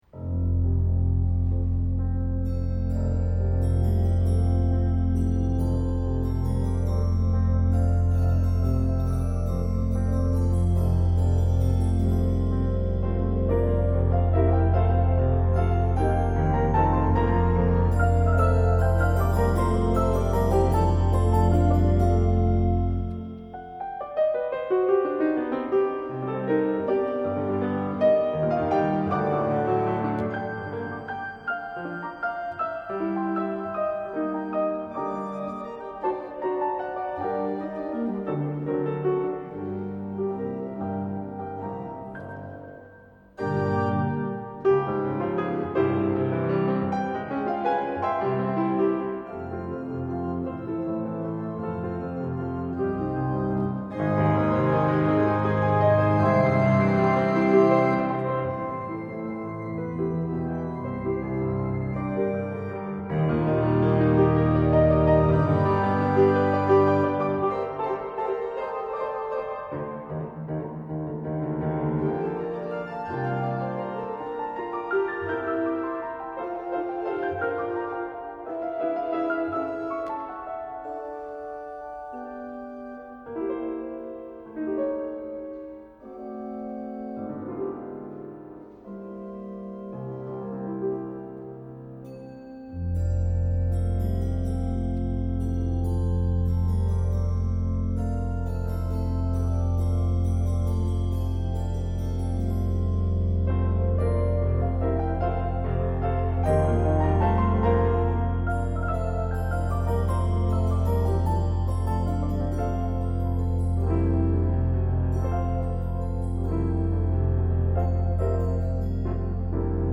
Scherzino für Orgel und Klavier (aus "Silhouetten" op. 29)
Konzertmitschnitt vom 6.4.2003 aus der Christuskirche Saarbrücken (Orgel: Mühleisen-Straßb./2Man/mech)
Klavier